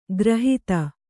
♪ grahita